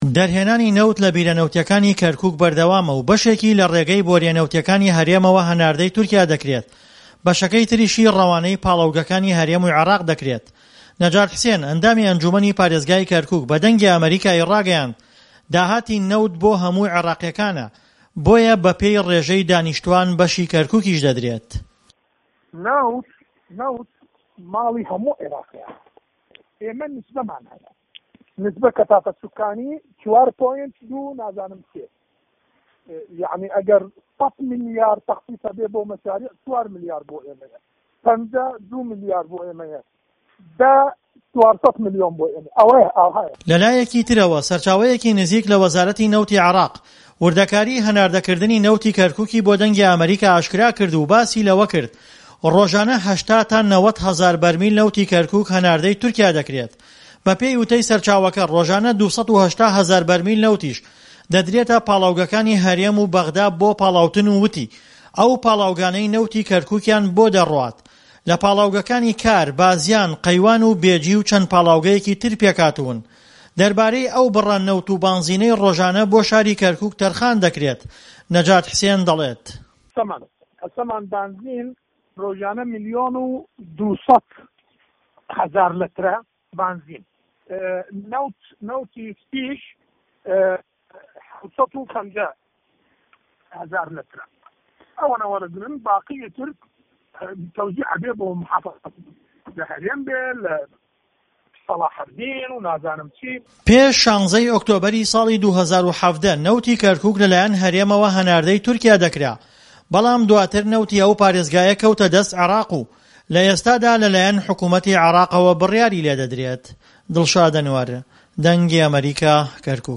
ڕاپۆرت - نەوت